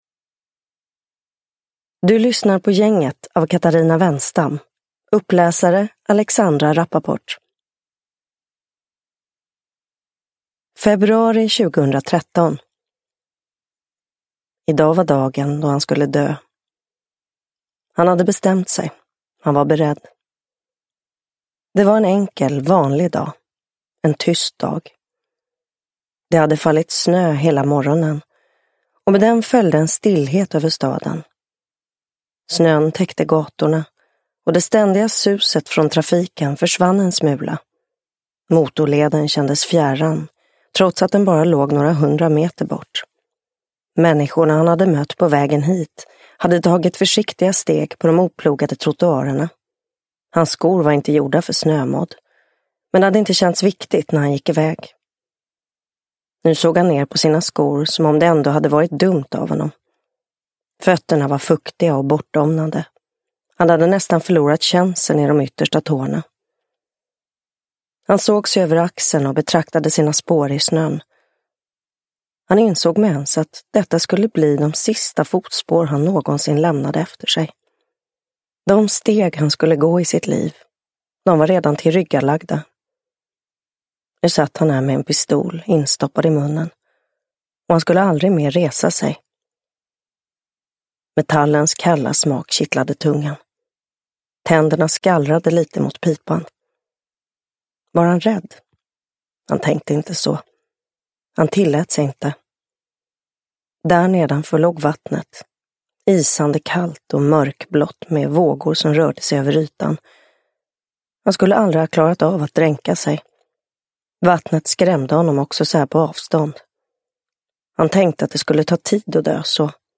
Uppläsare: Alexandra Rapaport
Ljudbok